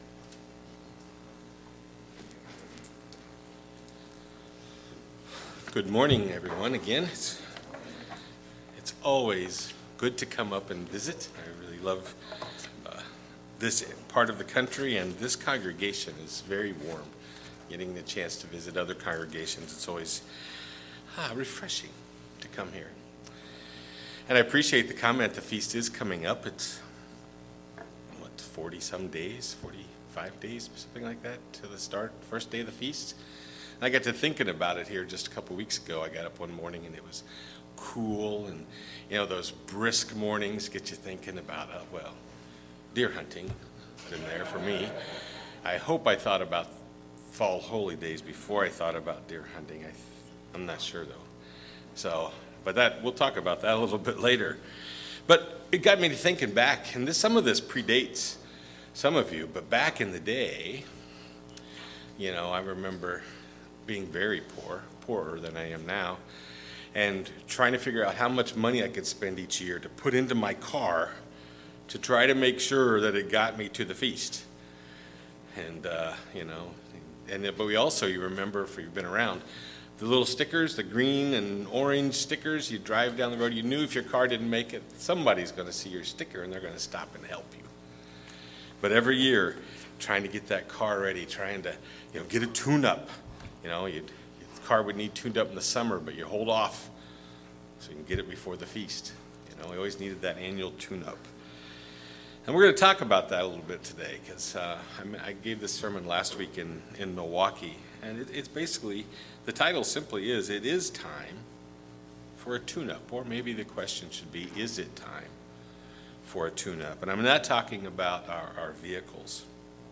Sermons
Given in Eau Claire, WI